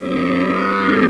wolt_pain4.wav